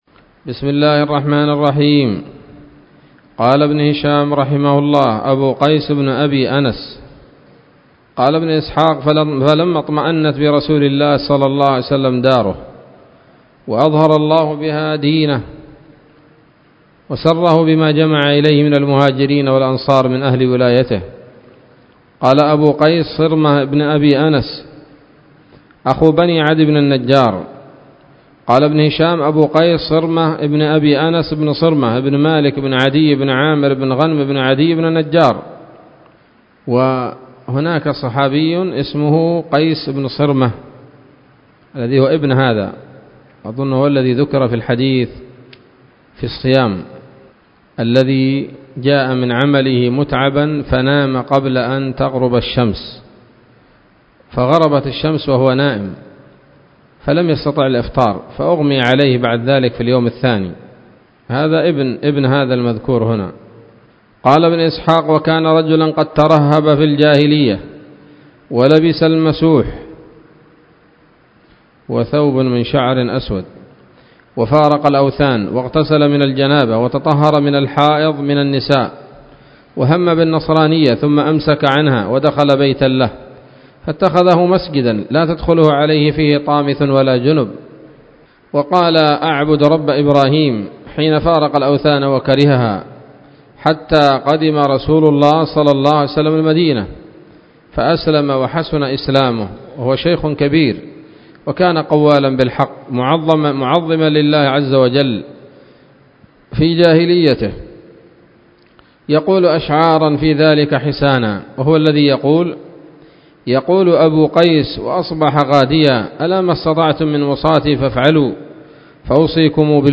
الدرس الحادي والثمانون من التعليق على كتاب السيرة النبوية لابن هشام